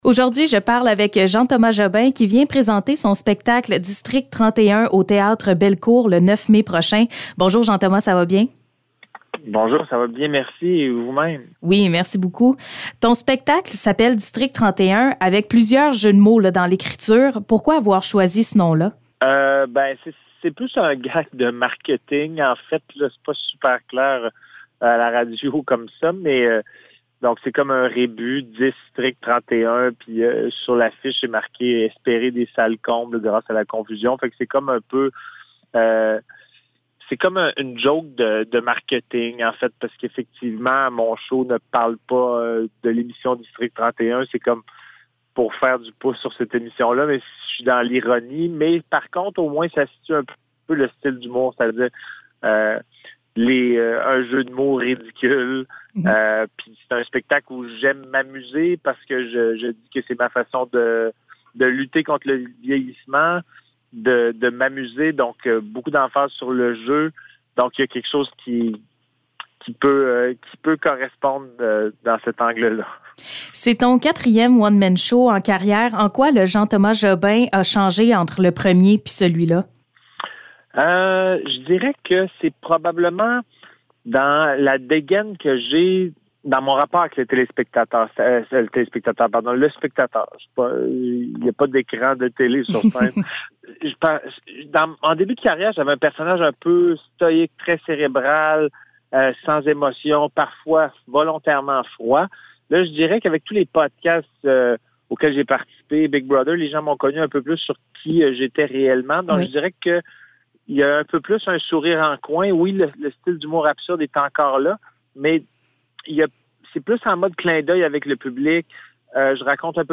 Entrevue avec Jean-Thomas Jobin